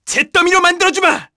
Neraxis-Vox_Skill5_kr.wav